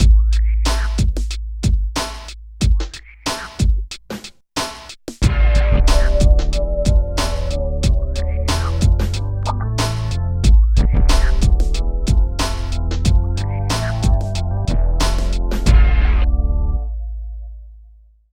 23 LOOP   -R.wav